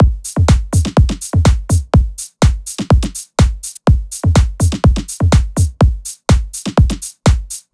Index of /99Sounds Music Loops/Drum Loops/Dance